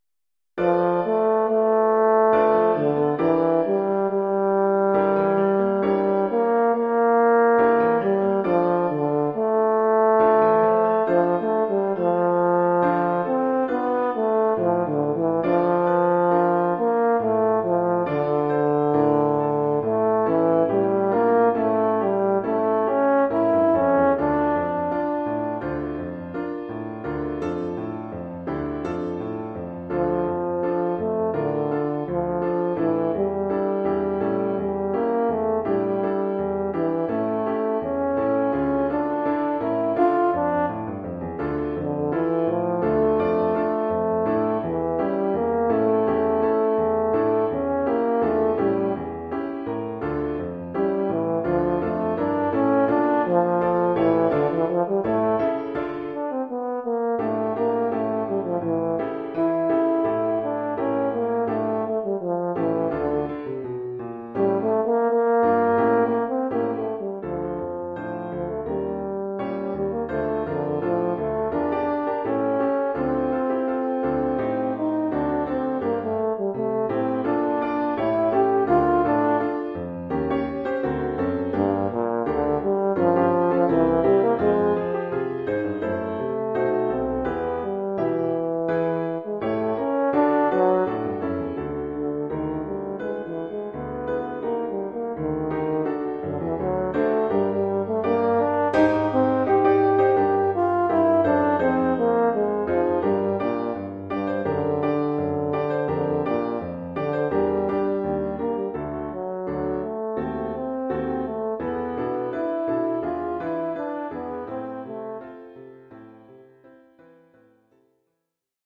Oeuvre pour cor d’harmonie et piano.